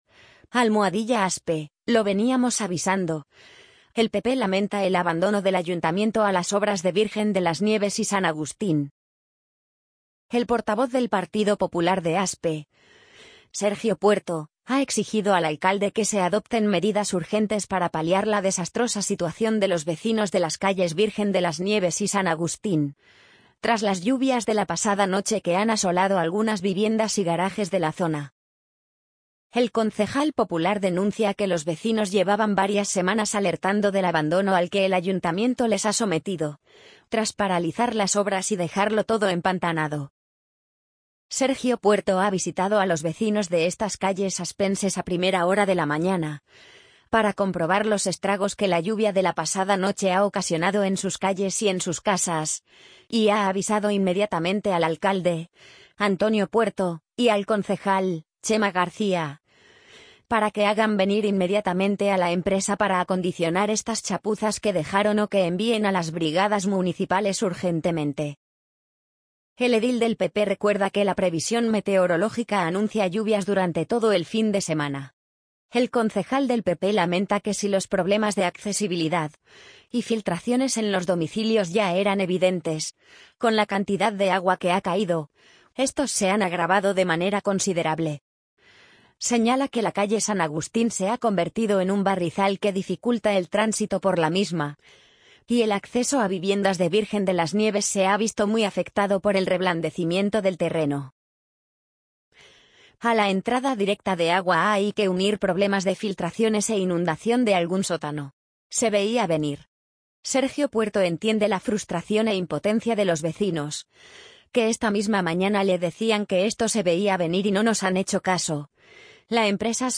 amazon_polly_54910.mp3